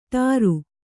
♪ ṭāru